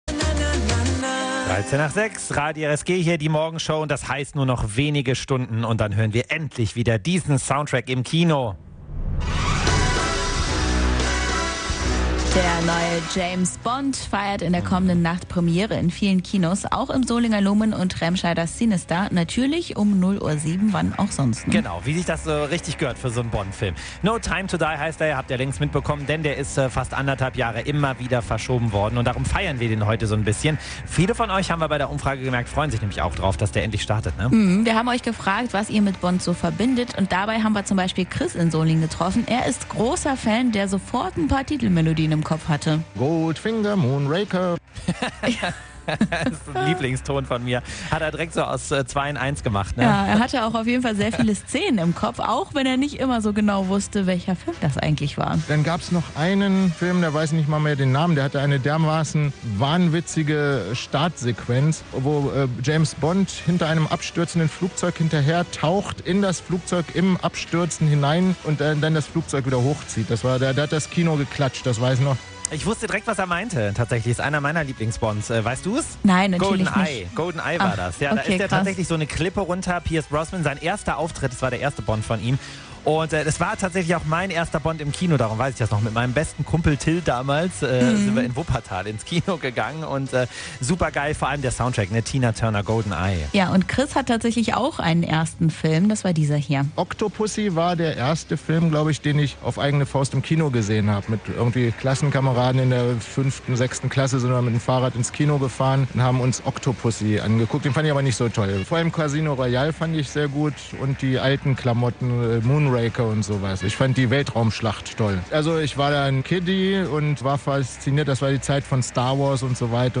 RSG-Hörer